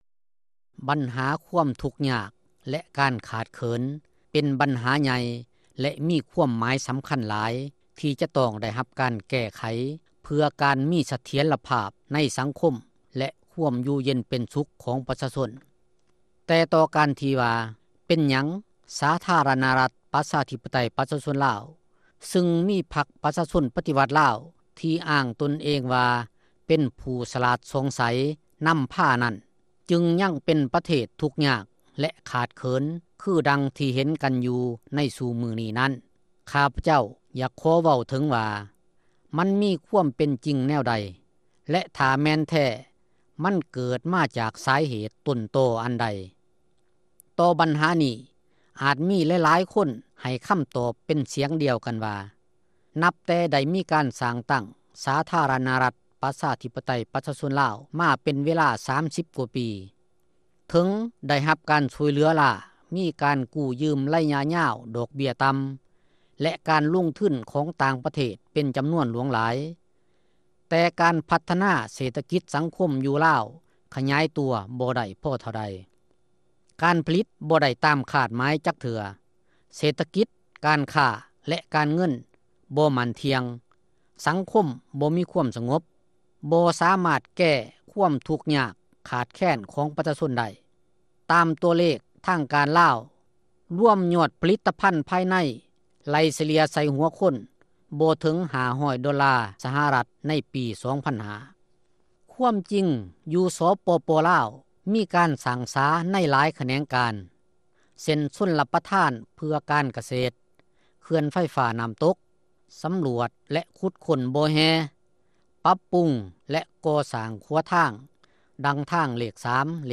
ວິຈານ ໂດຍ